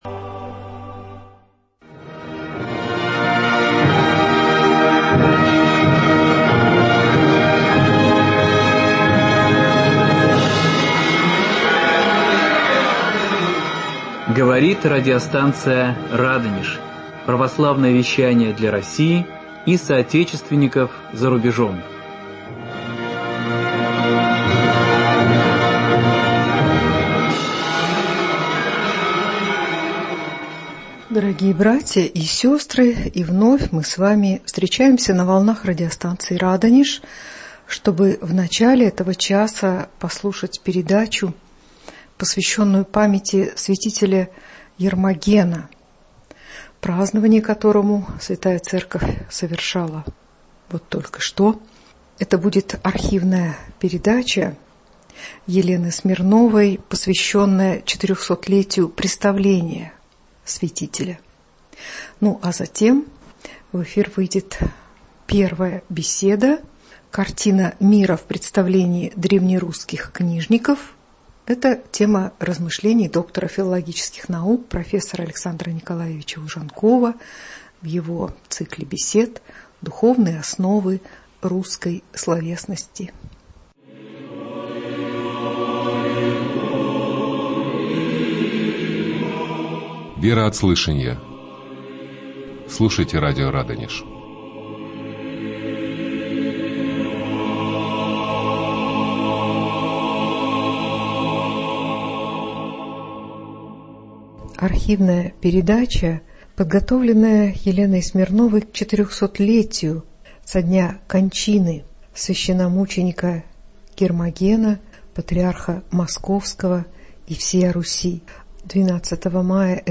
Рассмотрим представления древнерусских книжников о мироздании, времени, пространстве, бинарной картине мира, о власти и быте древнерусских людей, которые складываются в цельную картину мира, сформировавшуюся под влиянием принятого в конце X века христианства и господствовавшую на протяжении XI-XVII веков на Руси. В эфире беседа